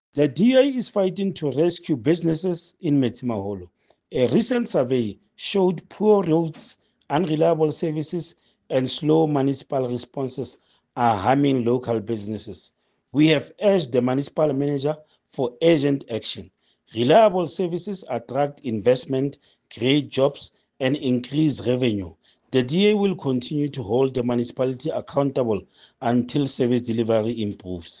Sesotho soundbites by Cllr Stone Makhema.